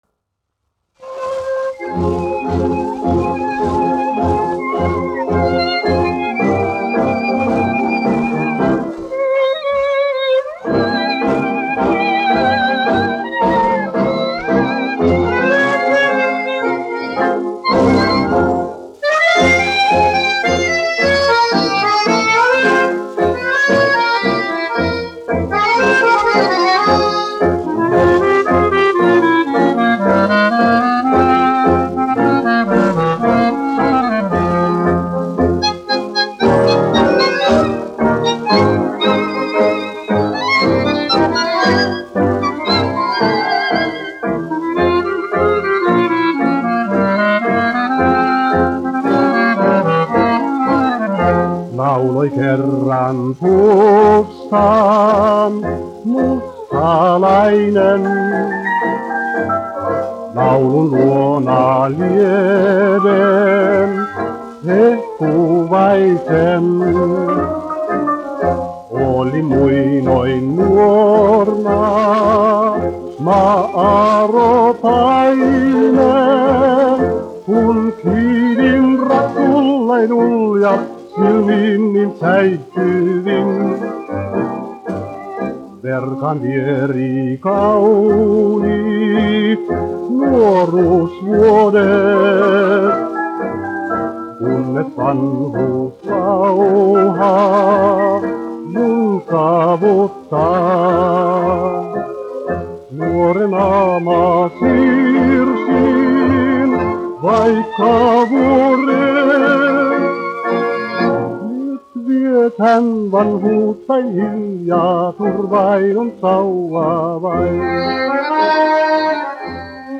1 skpl. : analogs, 78 apgr/min, mono ; 25 cm
Populārā mūzika--Somija
Skaņuplate